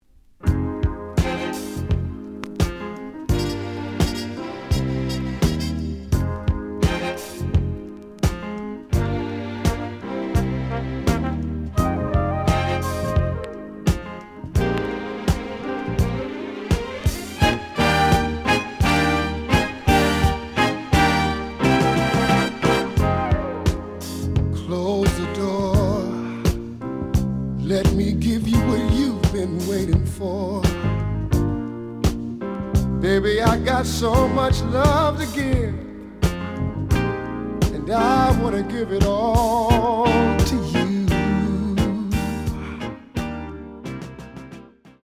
The audio sample is recorded from the actual item.
●Genre: Soul, 70's Soul
Some click noise on beginning of A side, but almost good.